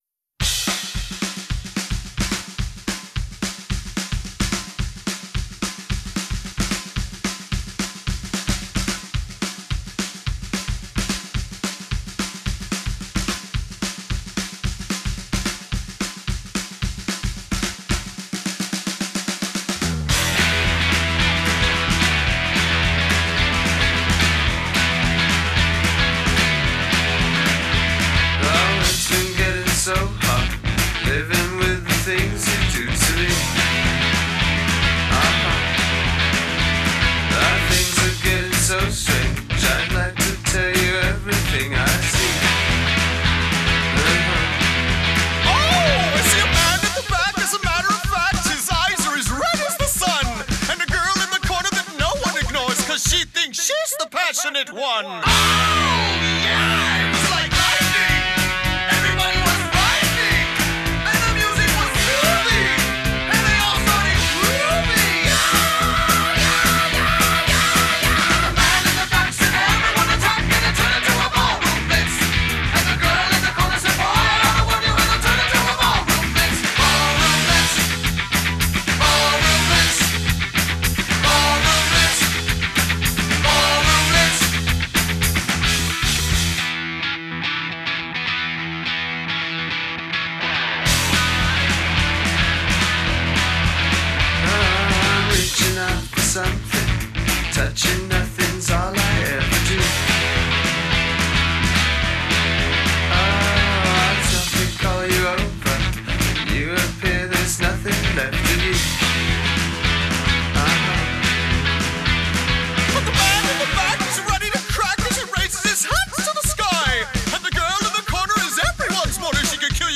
These are my mixes!!!
No band intro in beginning